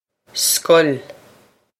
scoil skuh-ill
skuh-ill
This is an approximate phonetic pronunciation of the phrase.